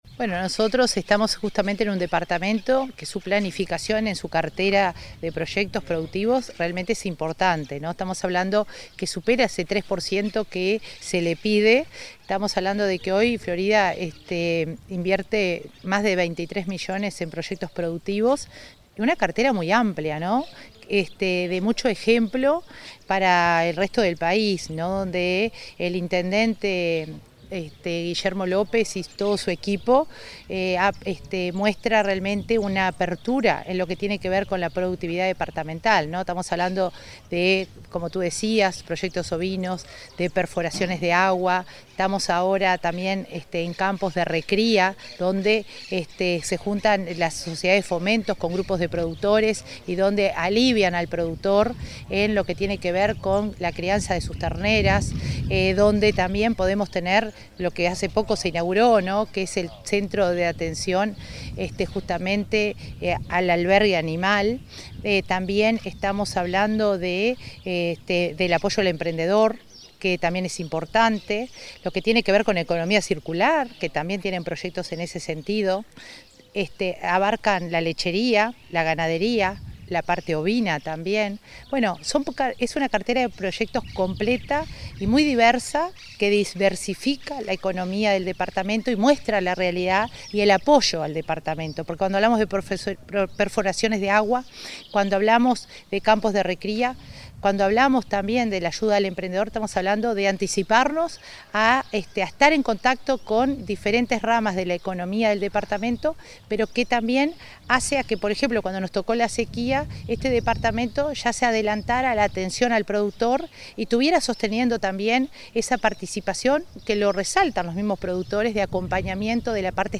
Entrevista a la coordinadora de Descentralización y Cohesión Social de OPP, María de Lima